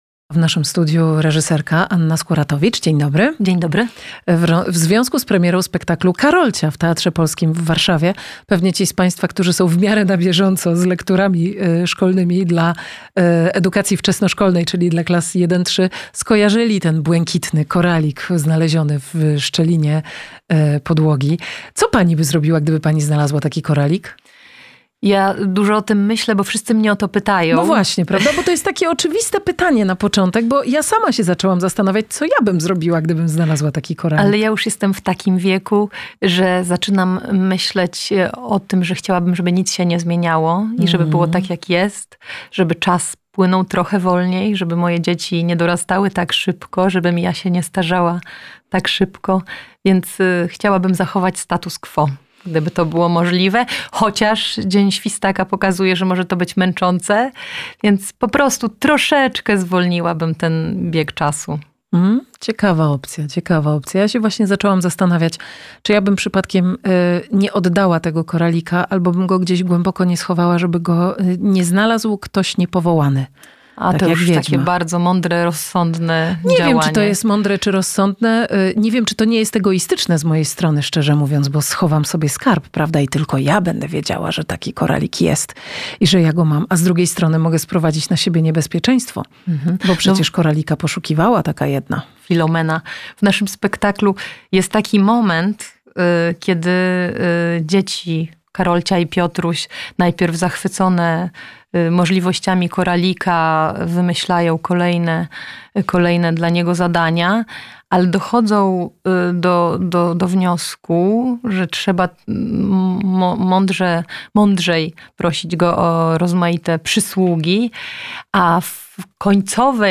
Posłuchaj audycji radiowej